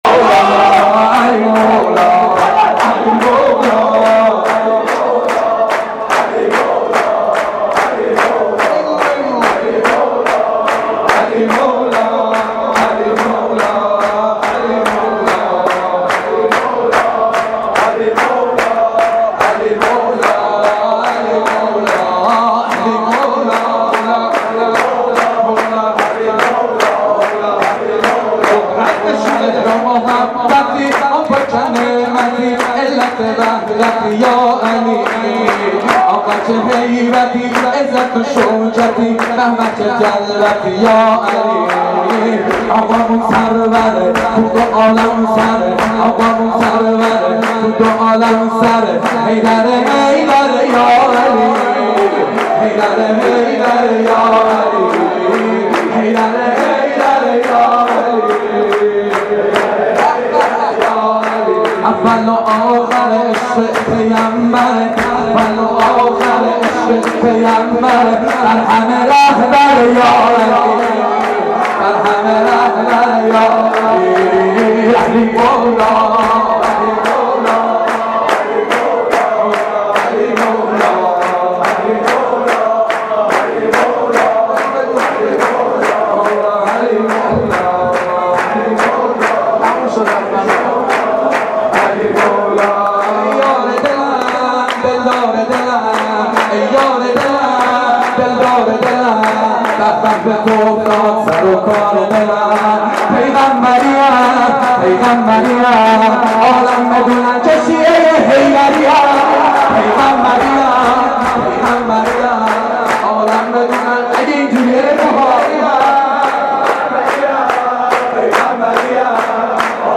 مولودی خوانی در مهدیه امام حسن(ع)